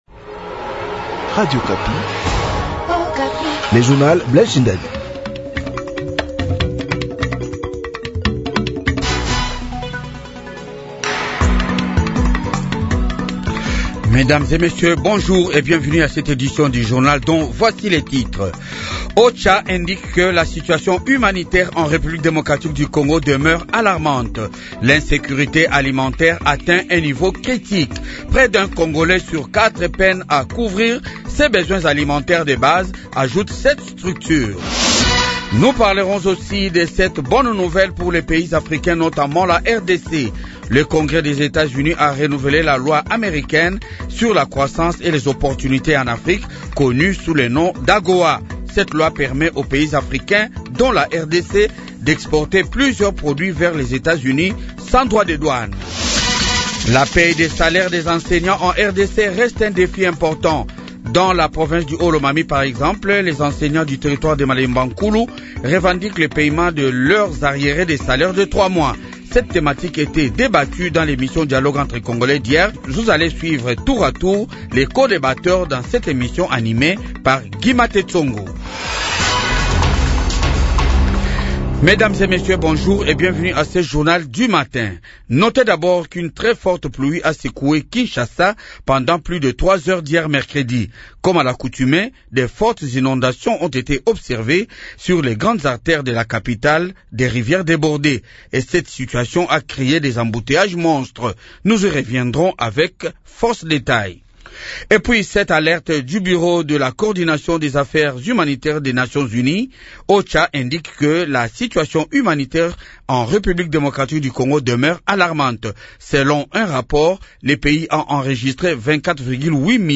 Journal du matin 6h